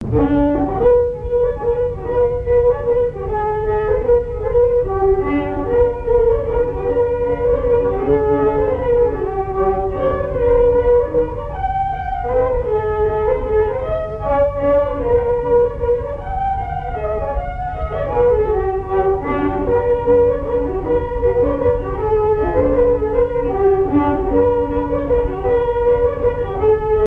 danse : mazurka
Airs à danser aux violons
Pièce musicale inédite